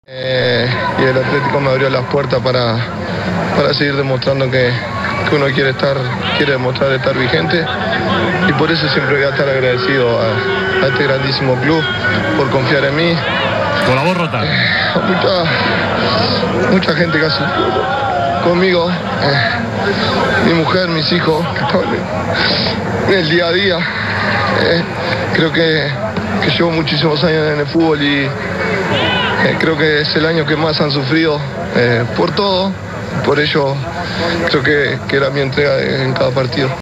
(Luis Suárez, autor del gol del triunfo, en entrevista con LaLiga)